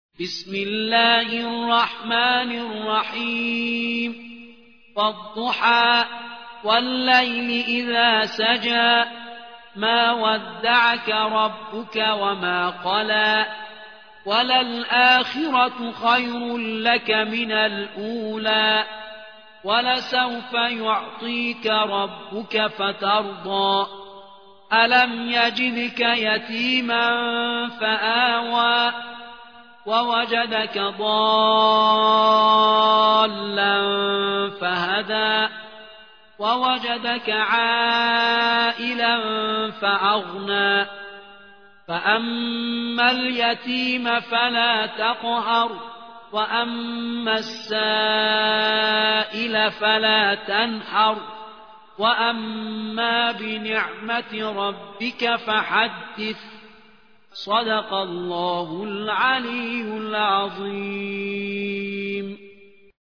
93. سورة الضحى / القارئ